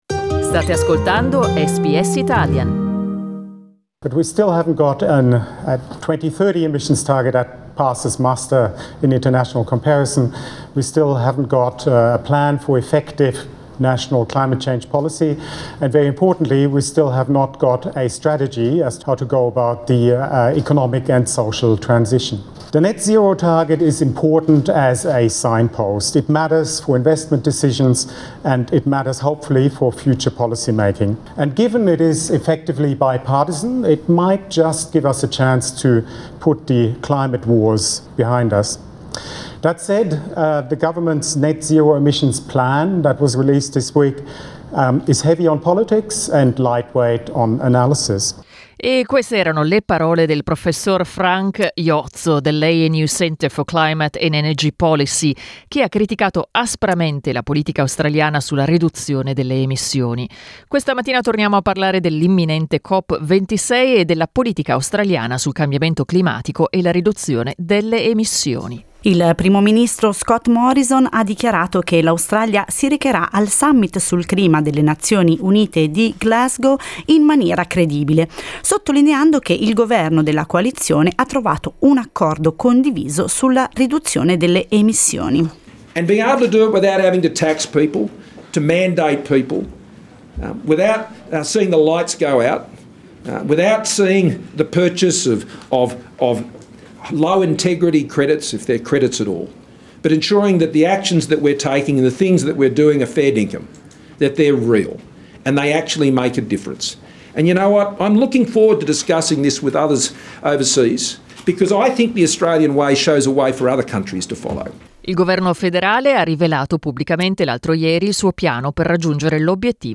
Ascolta l'intervista LISTEN TO L'Australian Way per arrivare a zero emissioni è efficace?